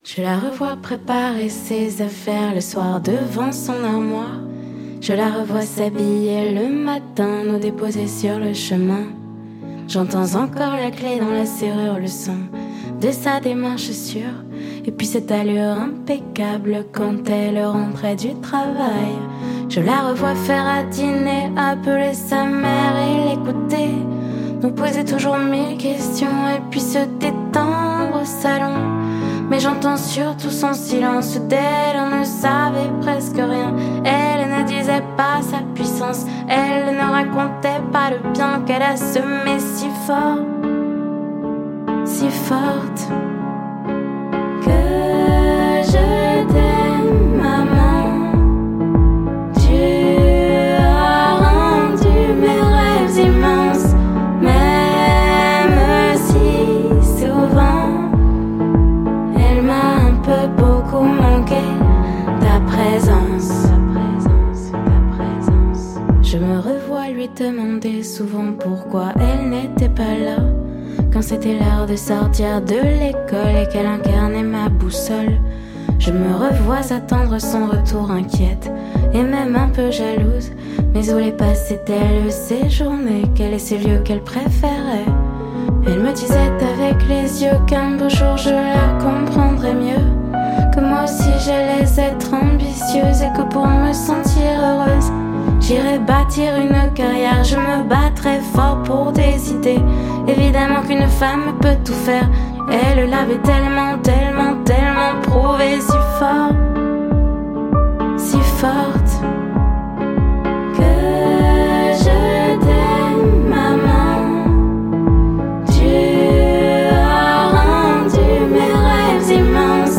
دانلود آهنگ پاپ فرانسوی